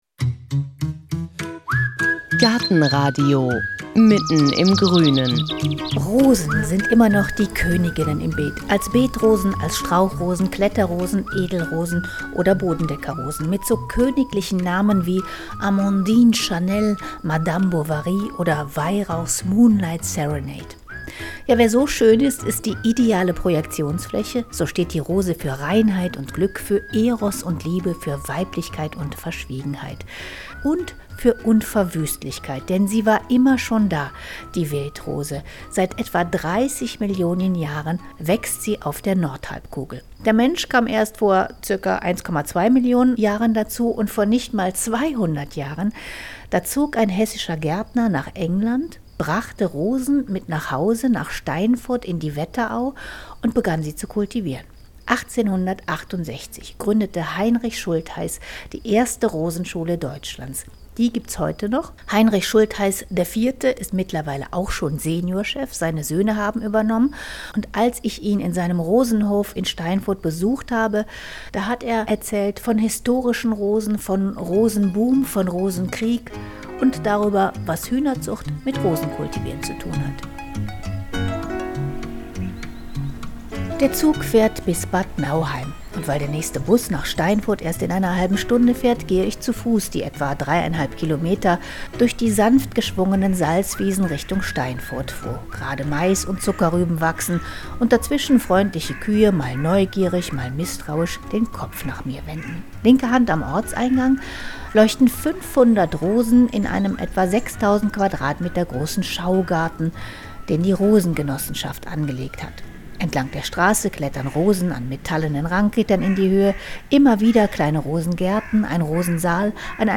Diesmal sind wir in Hessen unterwegs, im Rosendorf Steinfurth.